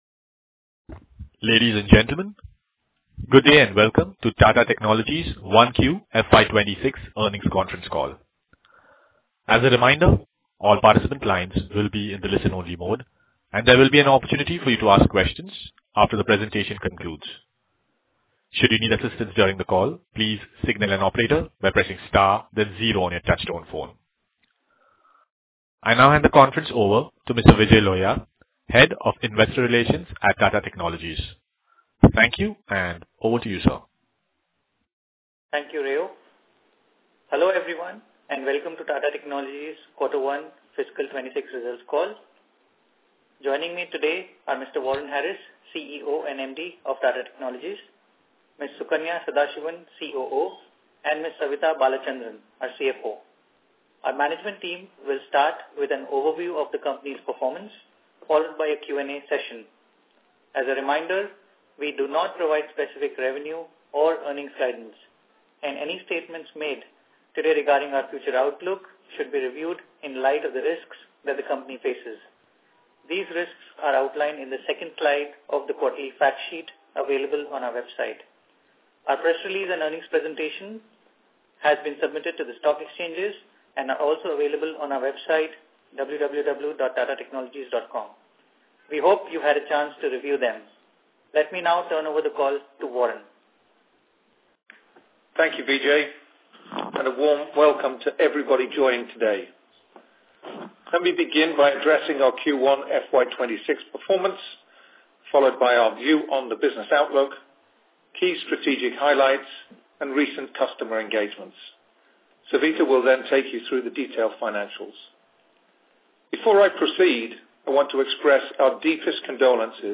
Earnings Call recording